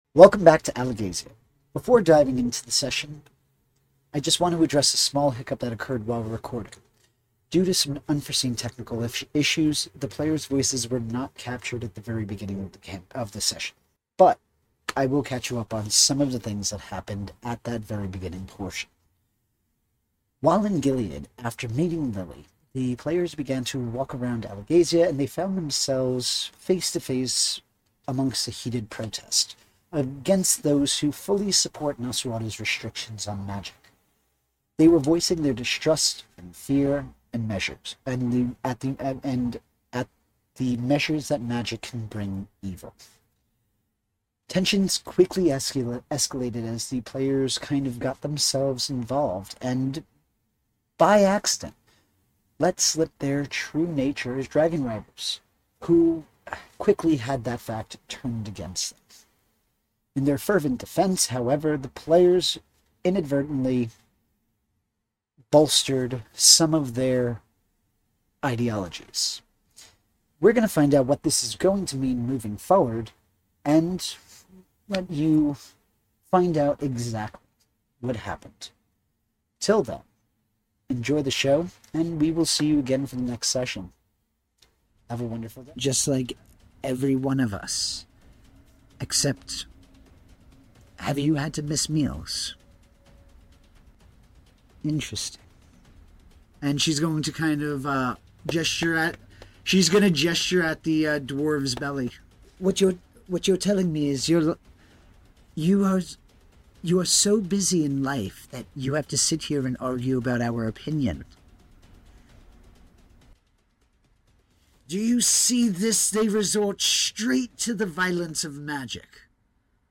Embark on an epic auditory journey through the mystical lands of Alagaësia in this immersive tabletop RPG podcast! Set ten years after the downfall of Galbatorix, heroes rise amid political intrigue, ancient mysteries, and powerful magic. Join our diverse group of adventurers as they navigate enchanted forests like Du Weldenvarden, rugged mountains of The Spine, and the shadowy deserts of Hadarac.